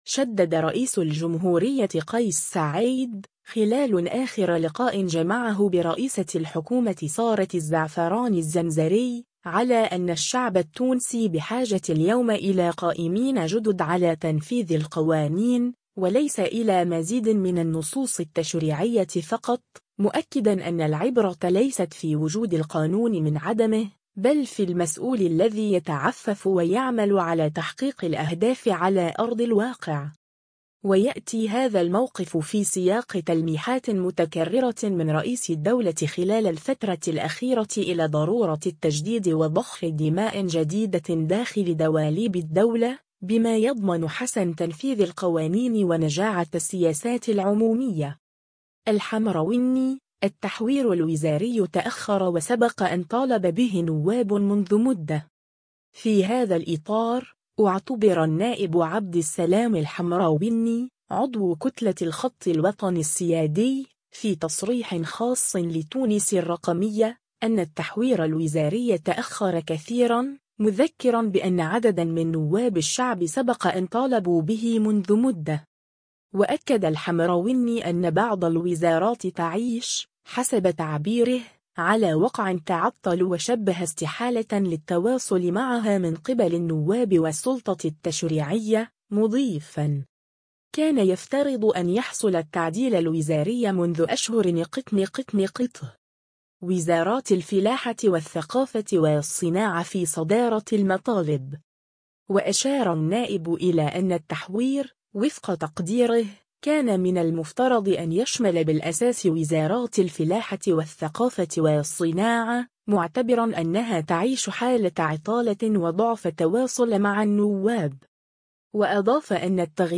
في هذا الإطار، اعتبر النائب عبد السلام الحمروني، عضو كتلة “الخط الوطني السيادي”، في تصريح خاص لـ”تونس الرقمية”، أن التحوير الوزاري تأخر كثيراً، مذكّراً بأن عدداً من نواب الشعب سبق أن طالبوا به منذ مدة.